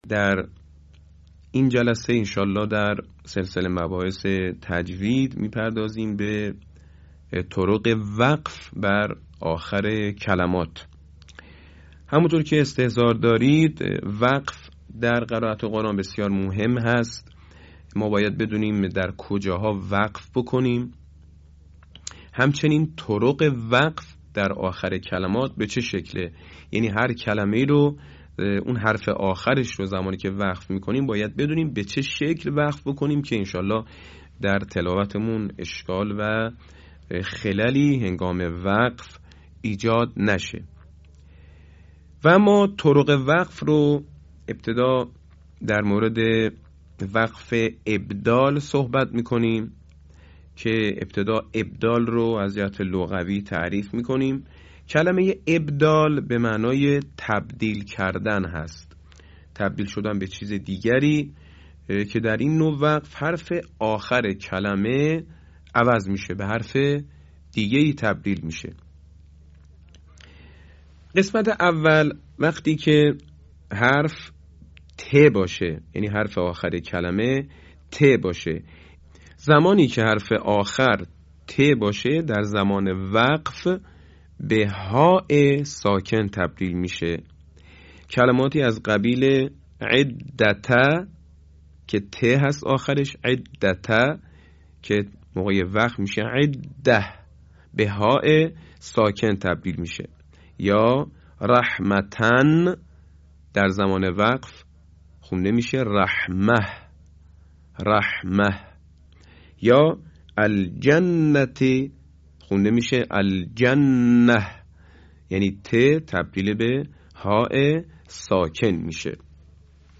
صوت | آموزش طرق وقف در آخر کلمات